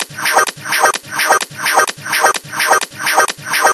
VEH1 Fx Loops 128 BPM
VEH1 FX Loop - 22.wav